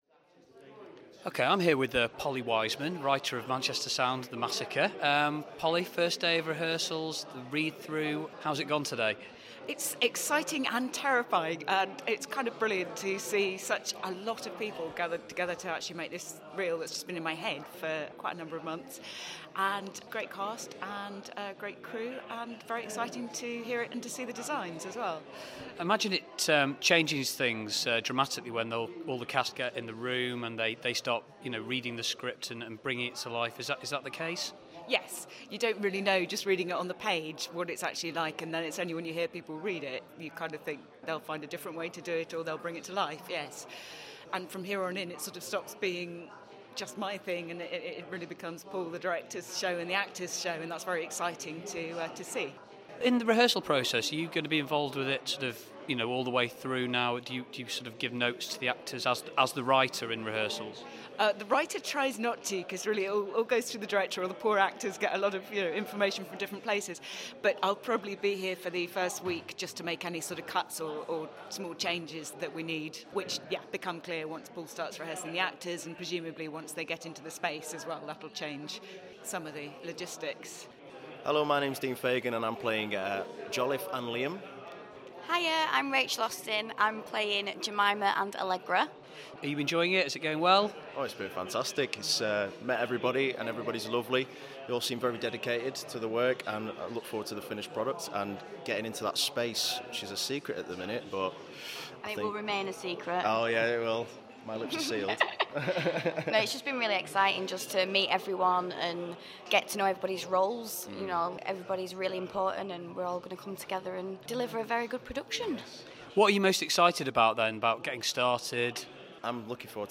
Manchester Sound: Day 1 behind the scenes